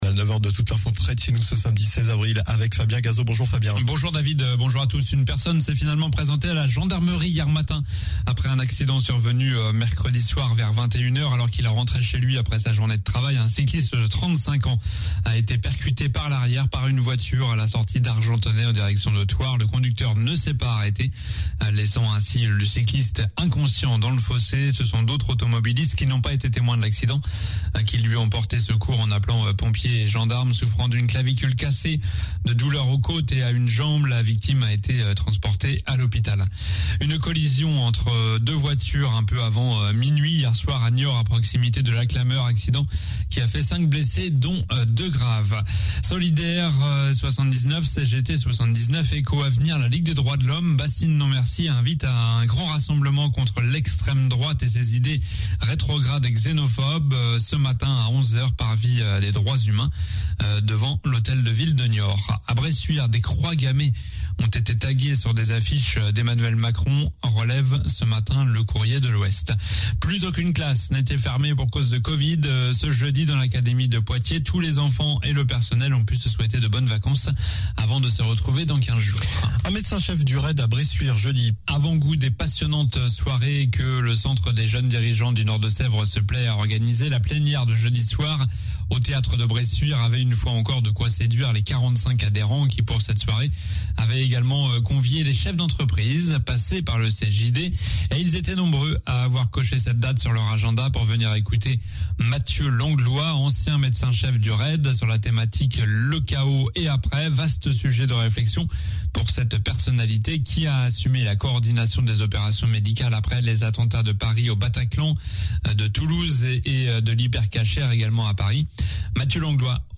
Journal du samedi 16 avril (matin)